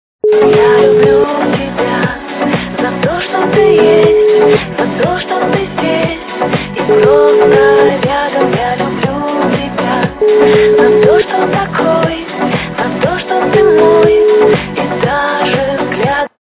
русская эстрада
ремикс качество понижено и присутствуют гудки.